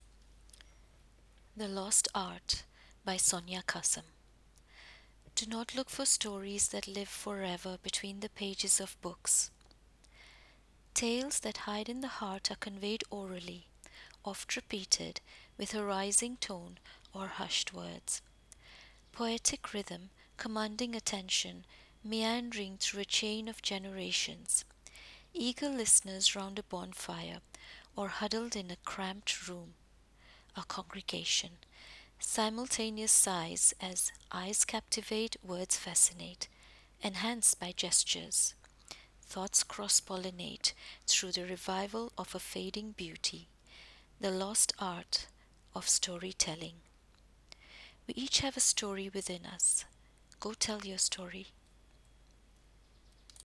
i felt it only appropriate to recite this poem.
What a beautiful recitation of this lovely poem!